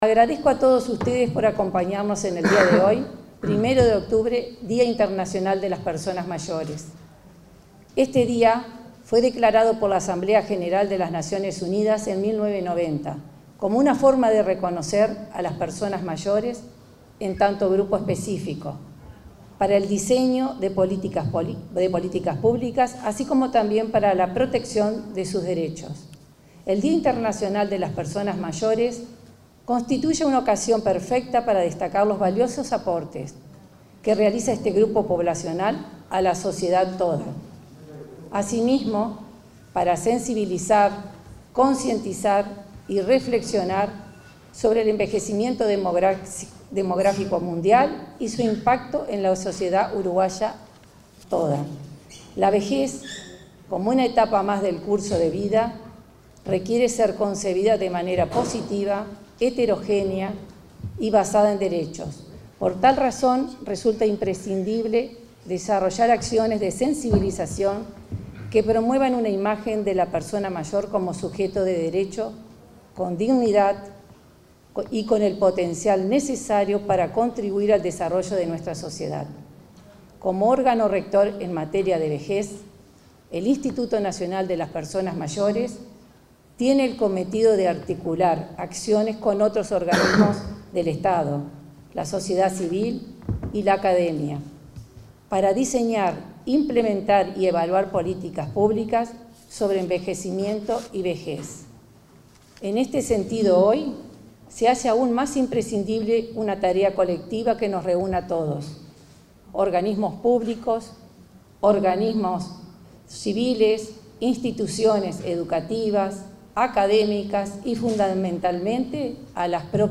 Palabras de autoridades del Mides
Palabras de autoridades del Mides 01/10/2021 Compartir Facebook X Copiar enlace WhatsApp LinkedIn La directora del Instituto Nacional de las Personas Mayores, Malva Torterolo; el secretario nacional de Cuidados y Discapacidad, Nicolás Scarela; y el ministro Martín Lema participaron, este viernes 1.°, de la conmemoración del Día Internacional de las Personas Mayores, realizado en la sede del Ministerio de Desarrollo Social (Mides).